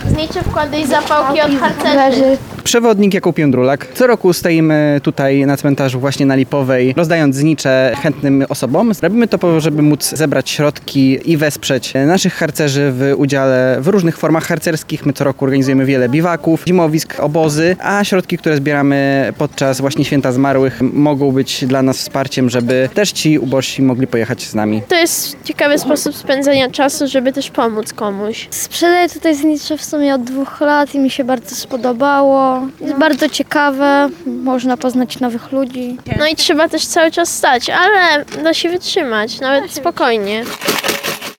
POSŁUCHAJ RELACJI DŹWIĘKOWEJ: